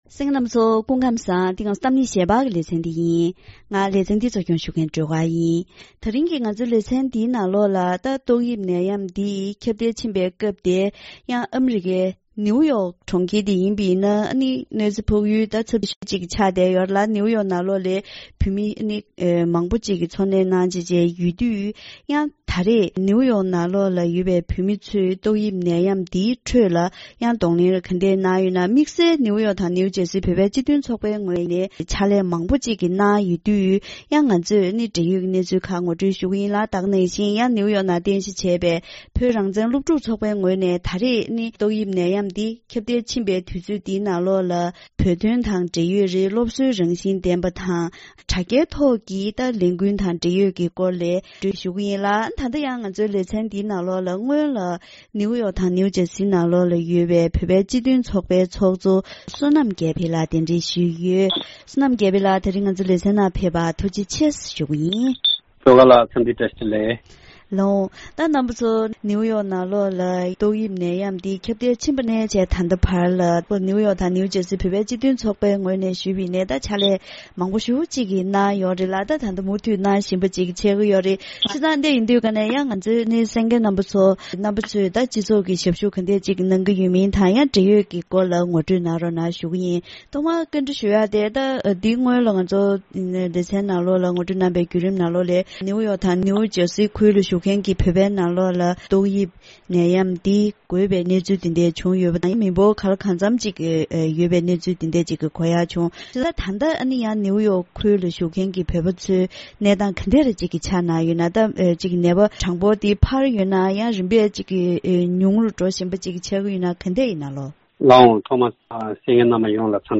ད་རིང་གི་གཏམ་གླེང་ཞལ་པར་ལེ་ཚན་ནང་ཏོག་དབྱིབས་འགོས་ནད་ཁྱབ་གདལ་ཕྱིན་པའི་སྐབས་ནིའུ་ཡོག་ཁུལ་དུ་བཞུགས་མཁན་བོད་མི་ཚོས་དཀའ་ངལ་ཁྱད་བསད་གནང་བཞིན་ཡོད་པ་དང་། དྲ་ལམ་བརྒྱུད་བོད་དོན་ལས་འགུལ་ཇི་ལྟར་སྤེལ་བཞིན་ཡོད་པའི་སྐོར་འབྲེལ་ཡོད་མི་སྣ་དང་ལྷན་དུ་བཀའ་མོལ་ཞུས་པ་ཞིག་གསན་རོགས་གནང་།